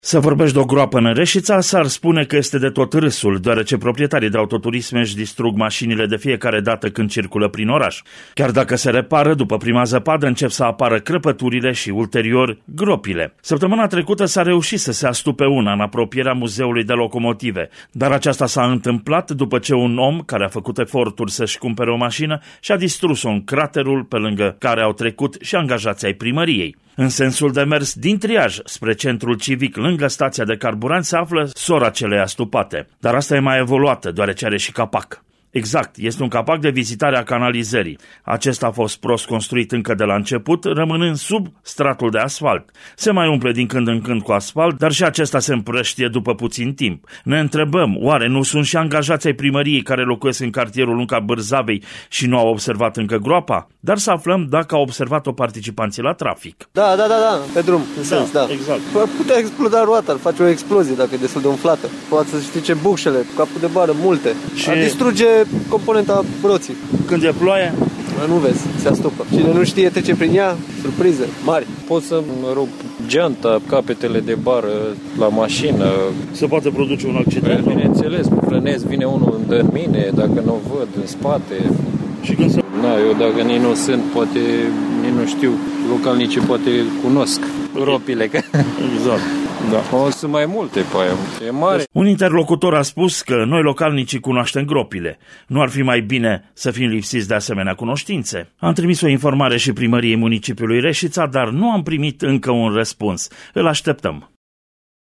Ascultaţi materialul audio difuzat în Jurnalul orei 17.00 de vineri, 5 ianuarie, la Radio România Reşiţa: